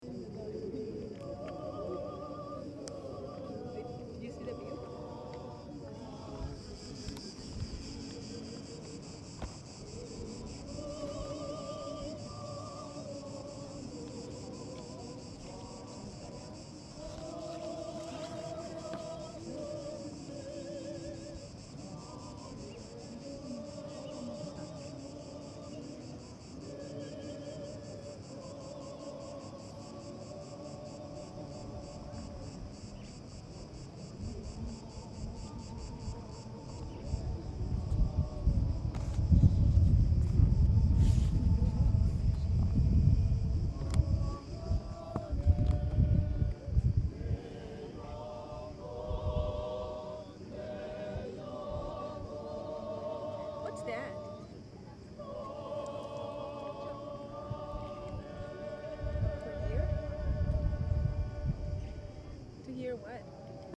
10/09/2014 15:00 Perchée sur un rocher dominant toute la ville, j'observe l'acropole.
Un groupe de touristes chinois, manifestement une chorale, se met à chanter. Une touriste américaine me demande ce que j'enregistre.